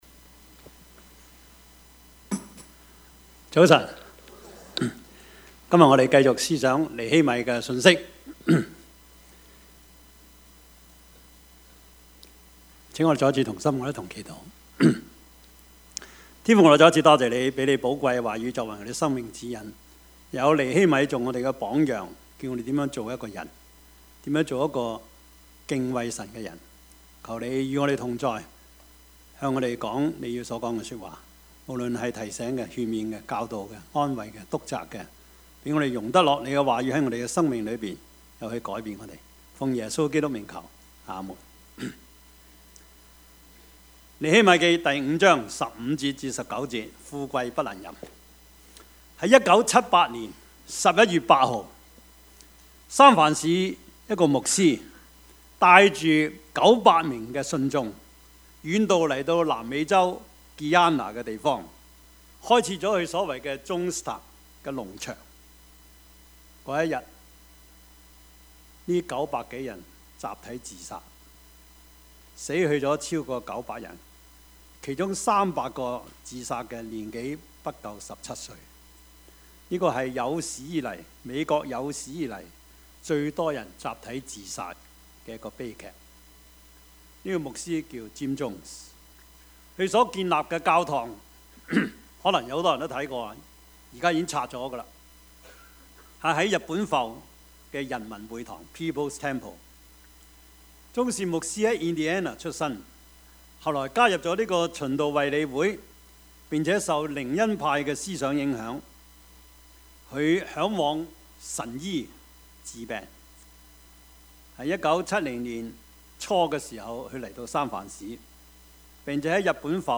Topics: 主日證道 « 橙黃橘綠 彼拉多的良心與掙扎 »